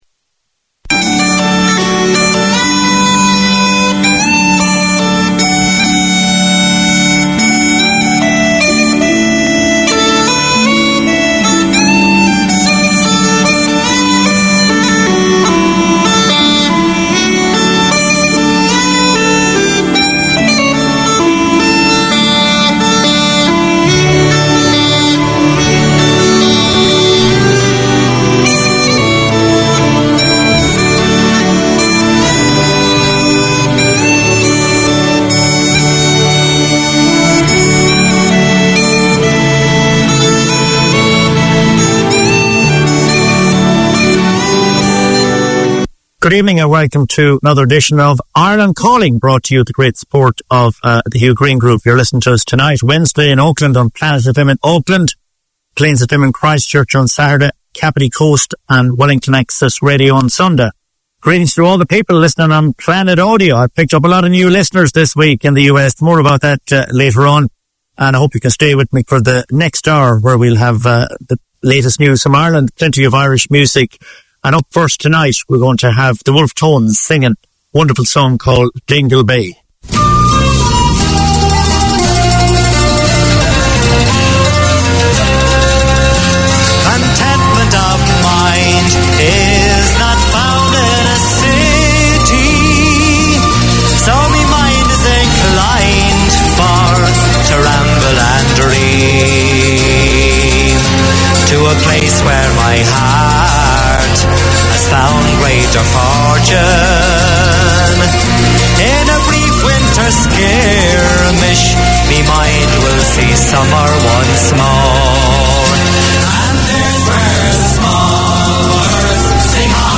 Featuring a wide range of Irish music and the occasional guest, including visiting Irish performers, politicians, sports and business people. A weekly Irish hour since 1990.